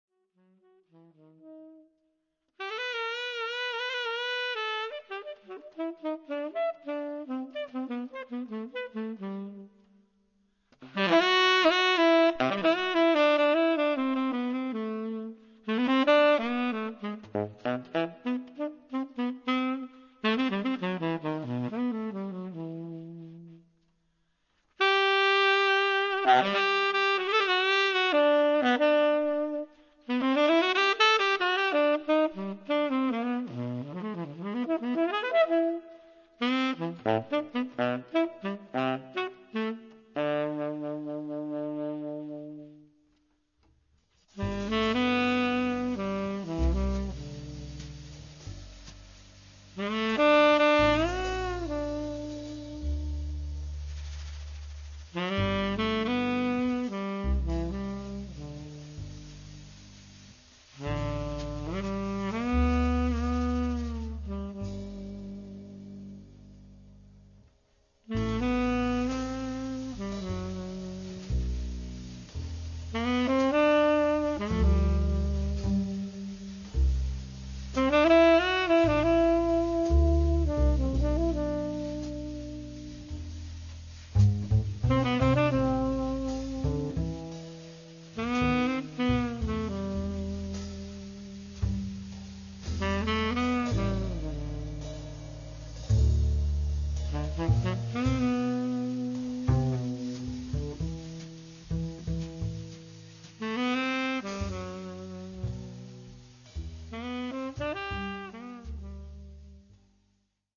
tenor saxophone
bass
drums